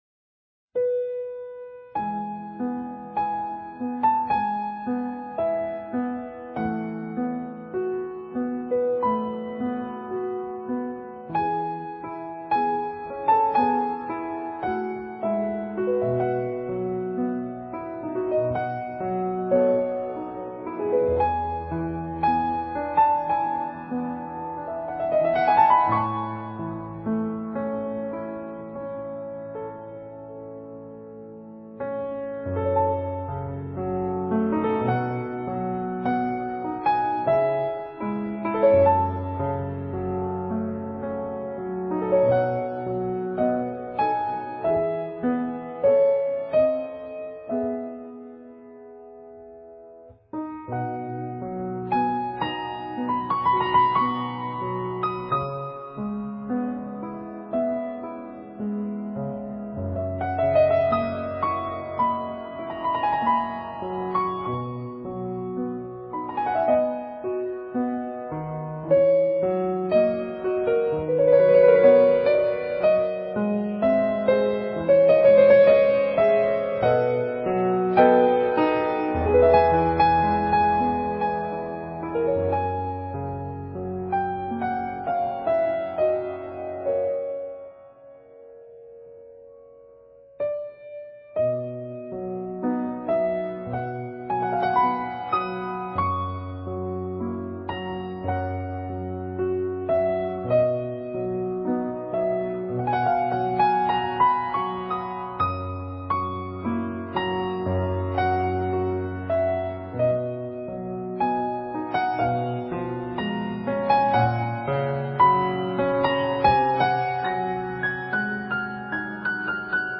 3021relaxpiano.mp3